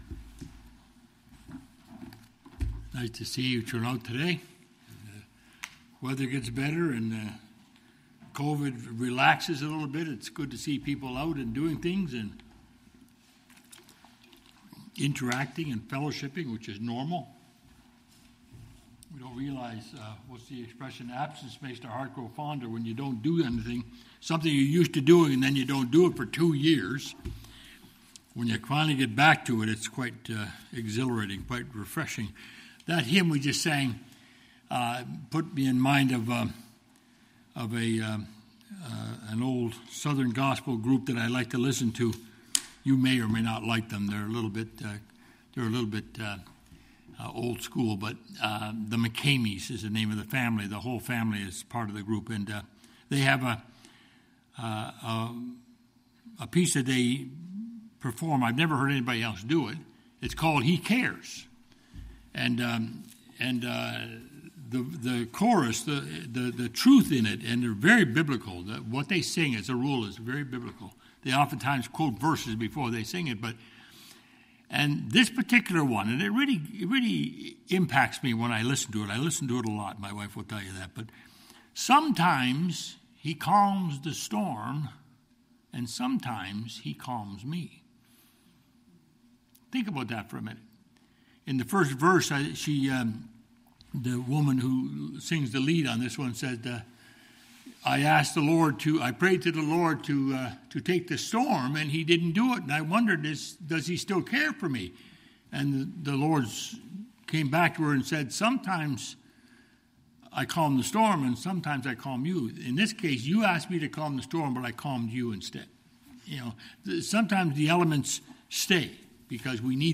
John 6:1-13 Service Type: Family Bible Hour Part of a series looking at the miracles of Jesus found in the Gospel of John.